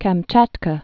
(kăm-chătkə, -chät-, kəm-chyät-)